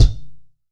B.B KICK 1.wav